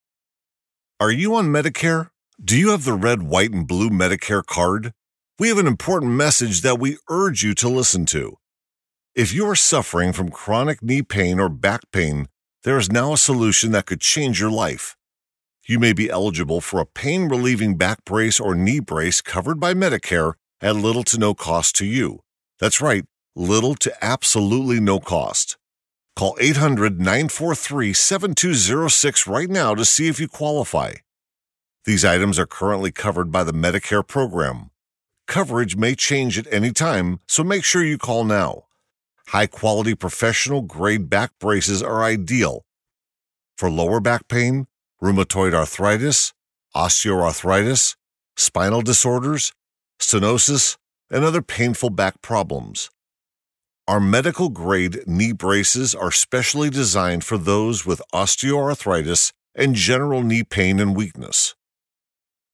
健身宣传（美式）-纵声配音网
外籍男10 健身宣传（美式）
来自美国，美式英语。...